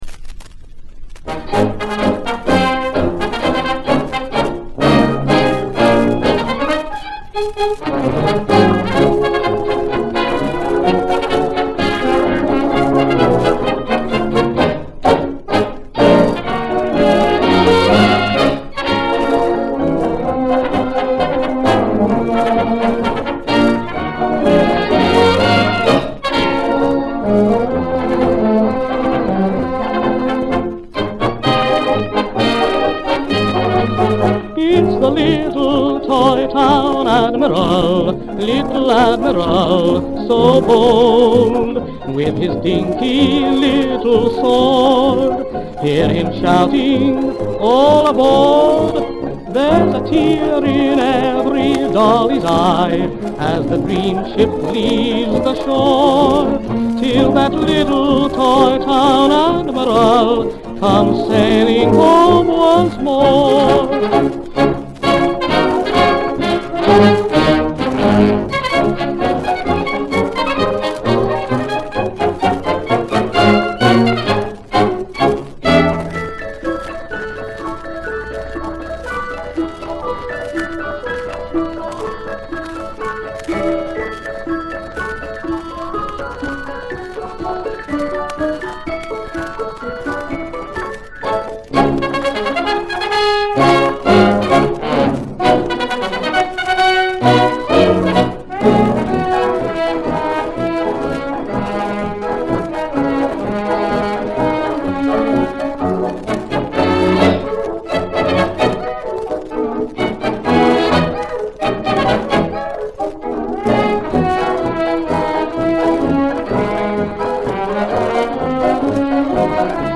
jaunty melody